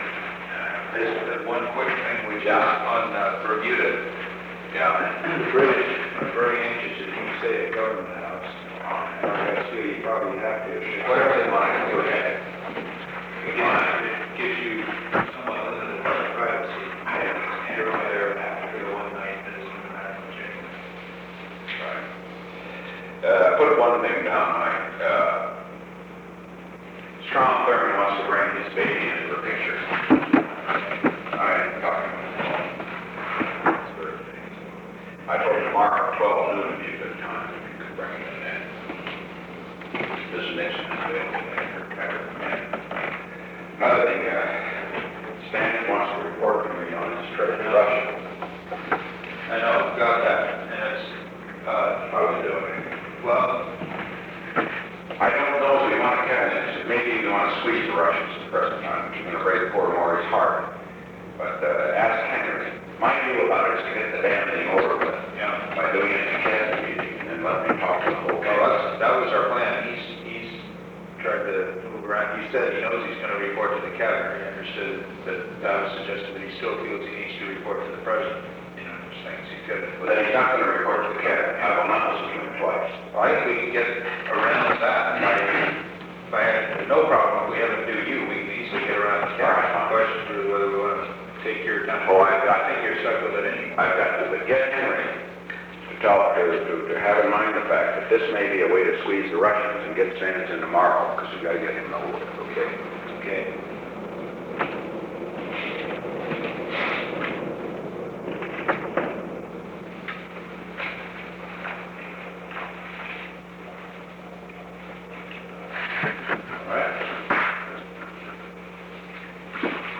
Recording Device: Oval Office
On December 6, 1971, President Richard M. Nixon, H. R. ("Bob") Haldeman, and Rose Mary Woods met in the Oval Office of the White House from 3:30 pm to 3:34 pm. The Oval Office taping system captured this recording, which is known as Conversation 630-015 of the White House Tapes.